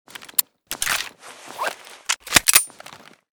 gsh18_reload_empty.ogg